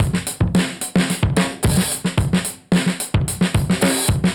Index of /musicradar/dusty-funk-samples/Beats/110bpm/Alt Sound